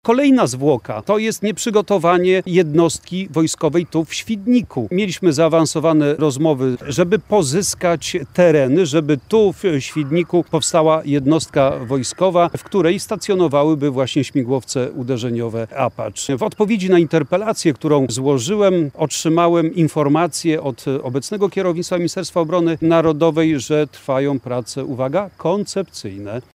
Obecny dziś w Świdniku były minister obrony narodowej, szef klubu PiS Mariusz Błaszczak jako przykład wskazywał plany dotyczące powstania bazy wojskowej w Świdniku.